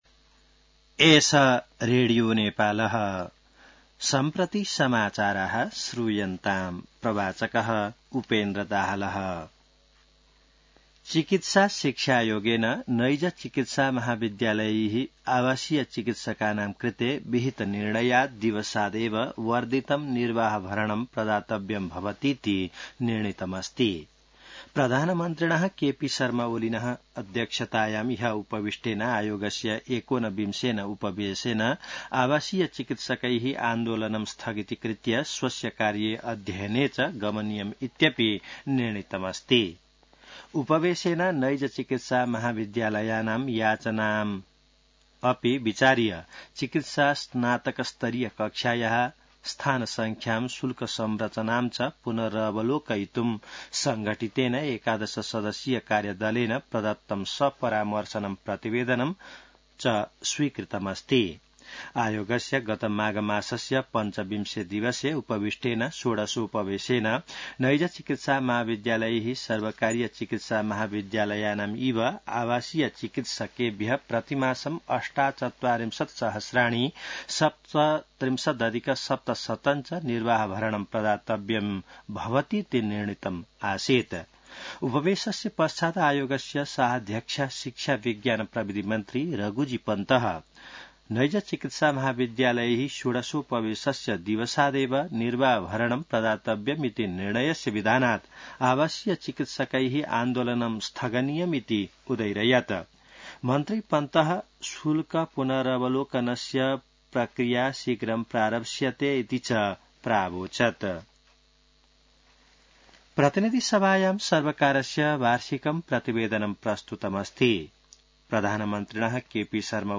संस्कृत समाचार : १६ वैशाख , २०८२